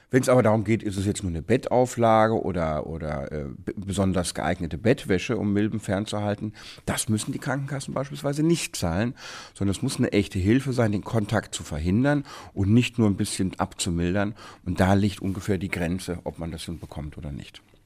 O-Ton: Hilfe gegen Hausstauballergie
O-Töne / Radiobeiträge, Ratgeber, Recht, , , ,